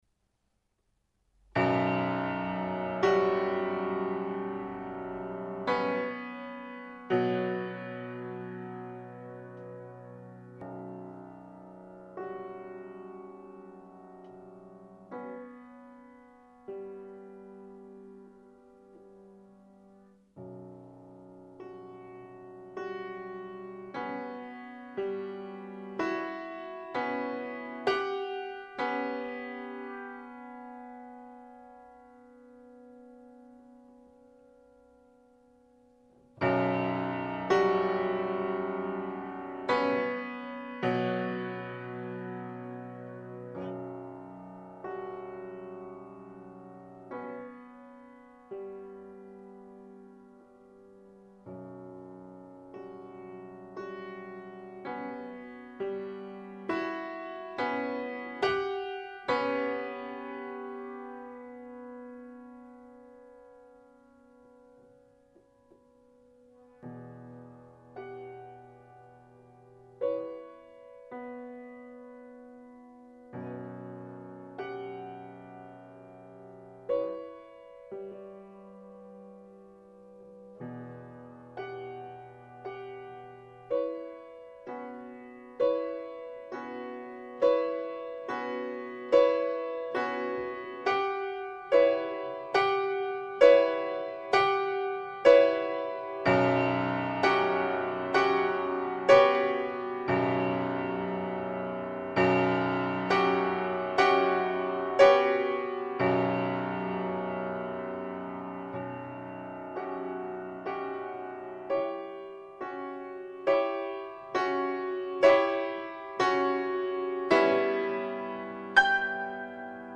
最後の裏連的な奏法など随所に箏の器楽的奏法を連想させる